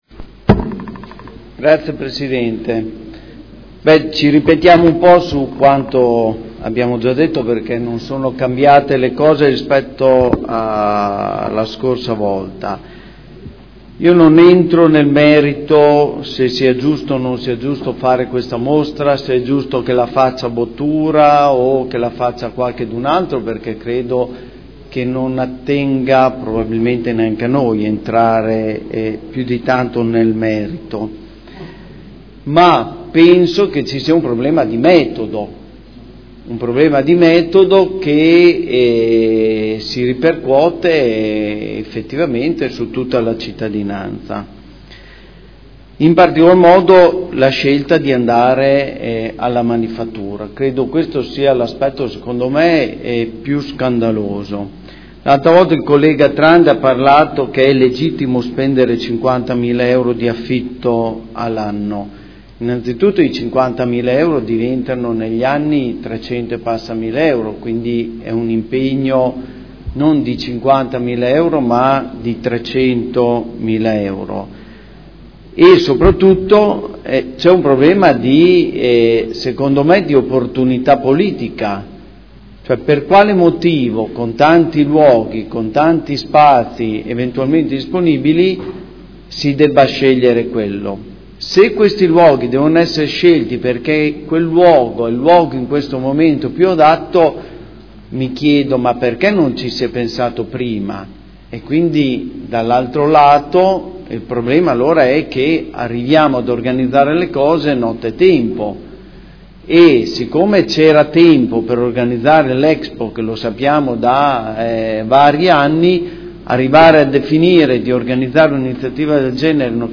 Seduta del 09/07/2015 Dibattito. Interrogazioni 81876, 83091, 85381 presentate da Scardozzi e Galli su Manifattura e mostre.